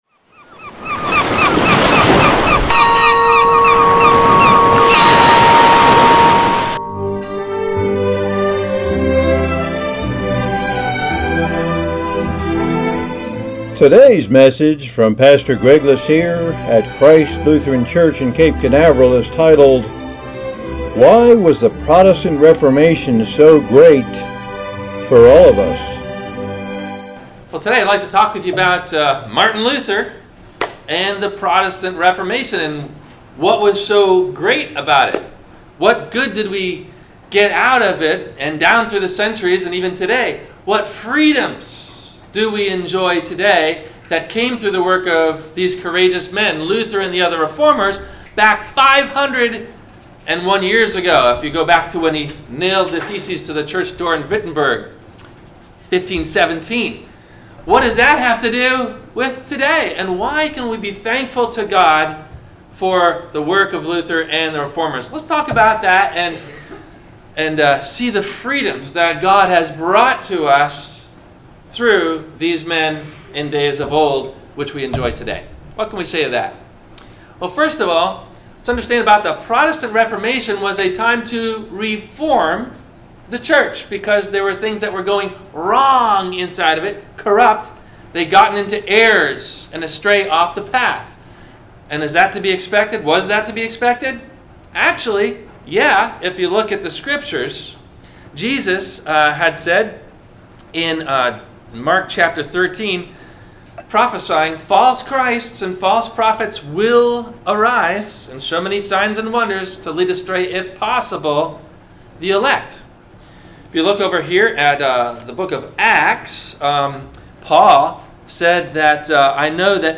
WMIE Radio – Christ Lutheran Church, Cape Canaveral on Mondays from 12:30 – 1:00
Questions asked before the Sermon message: